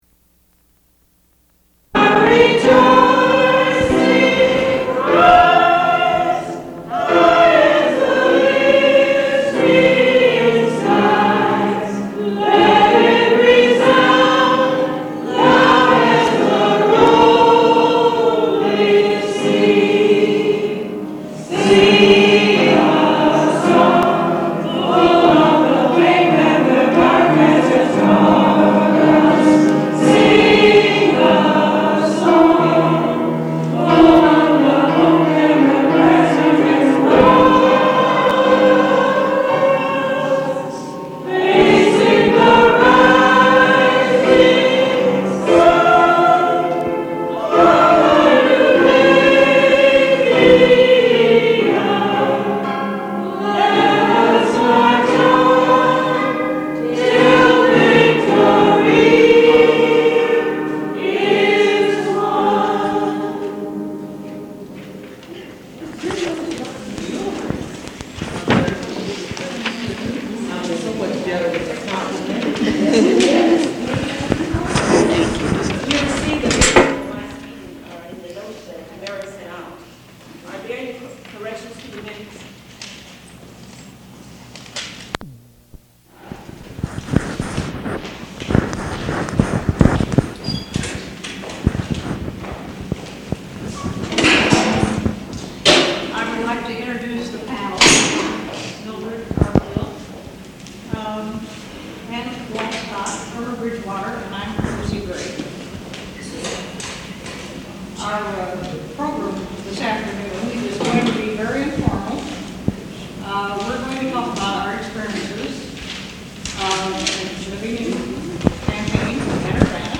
NEGROES IN CHAMPAIGN-URBANA Dublin Core Title NEGROES IN CHAMPAIGN-URBANA Description NEGROES IN CHAMPAIGN-URBANA [CALL NO.: CASSETTE/ILLINOIS/(CHAMP)/NAT] (.MP3 Audio File) Comments: AUDIOTAPE RECORDING (CA. 180 MINUTES DURATION IN TWO CASSETTES) OF A PANEL DISCUSSION PRESENTED FEBRUARY 12, 1983.